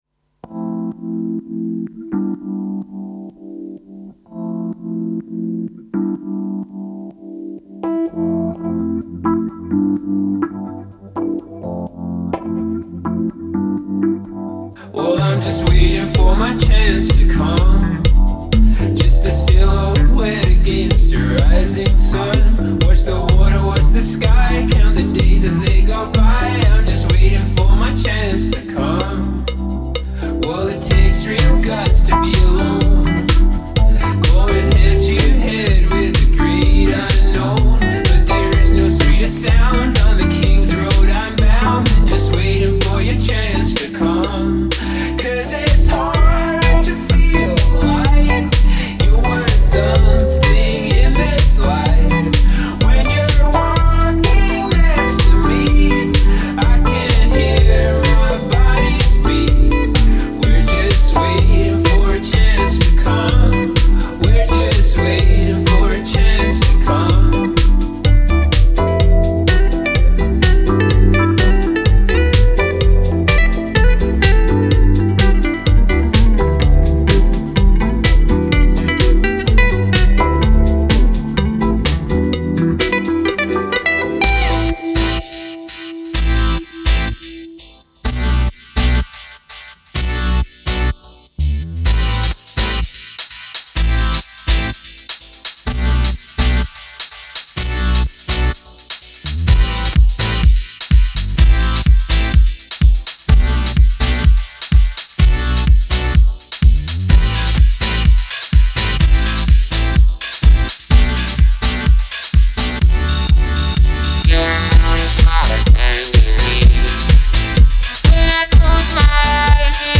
indie band